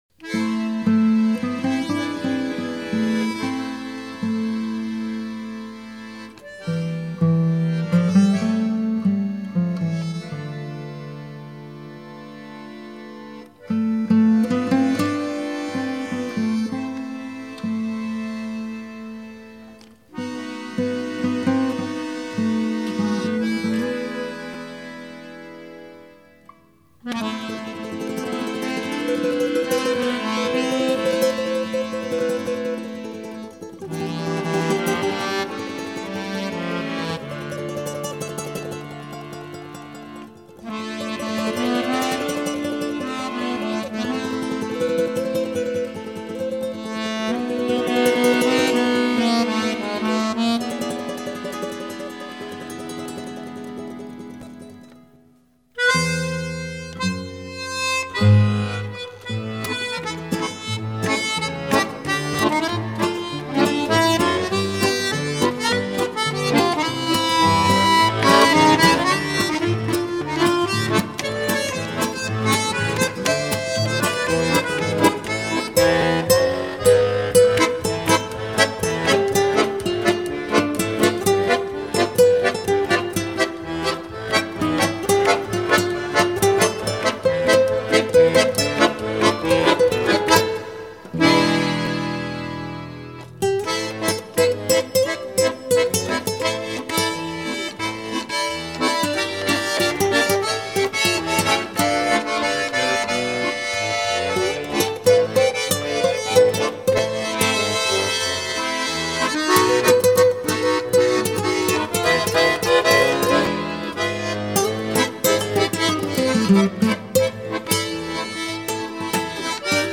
Гитарные пьесы
Саунд светлый и радостный, музыка лёгкая и доступная.
Русский городской романс